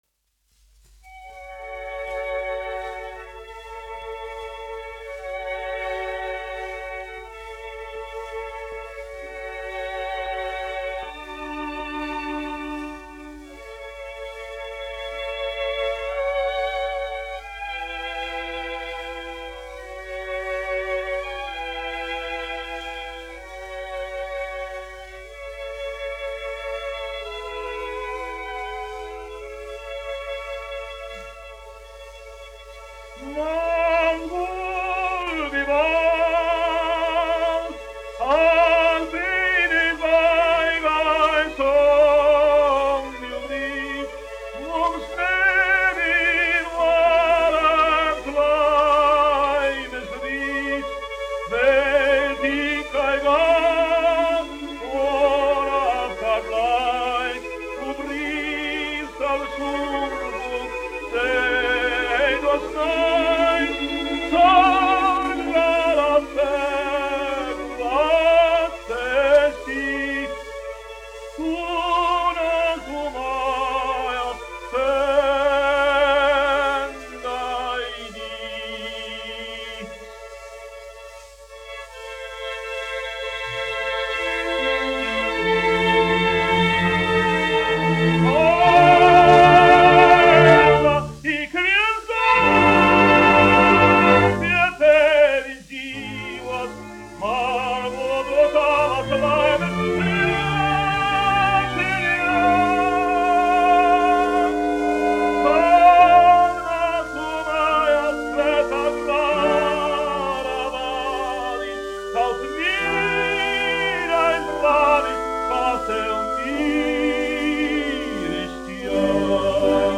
1 skpl. : analogs, 78 apgr/min, mono ; 30 cm
Operas--Fragmenti
Latvijas vēsturiskie šellaka skaņuplašu ieraksti (Kolekcija)